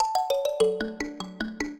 mbira
minuet13-3.wav